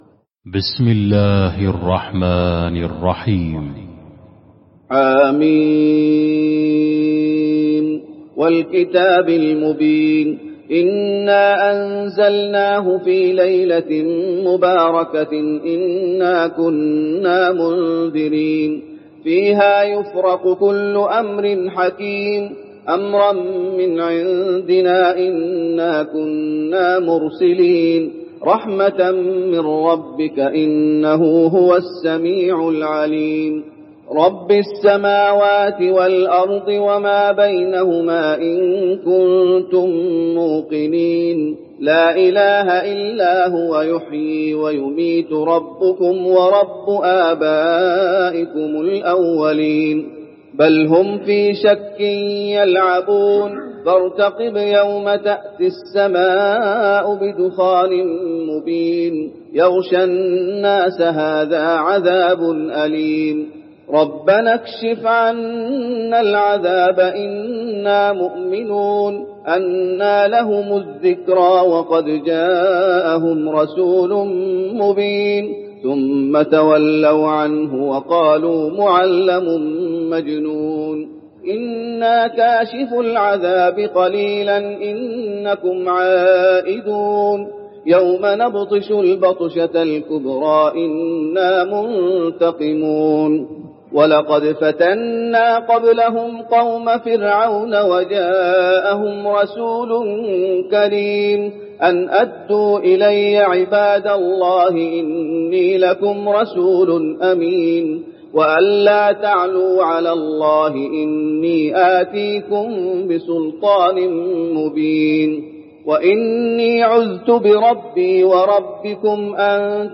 المكان: المسجد النبوي الدخان